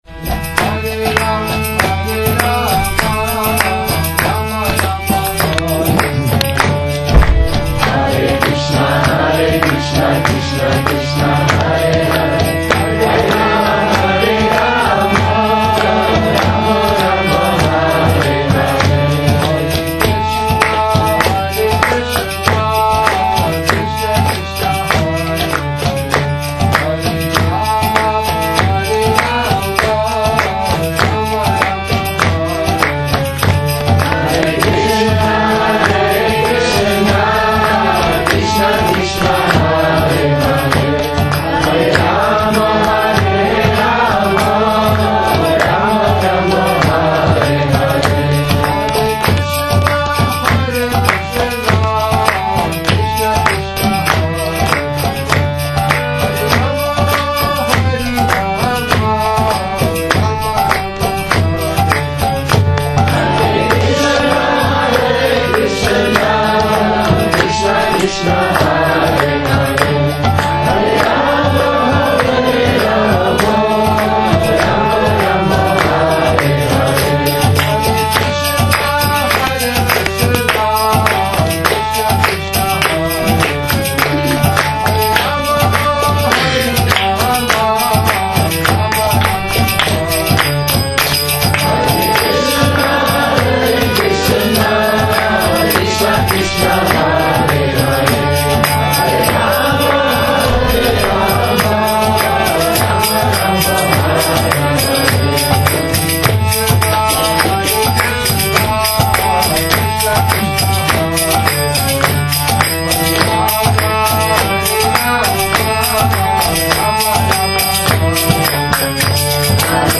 Kírtan 3 – Šrí Šrí Nitái Navadvípačandra mandir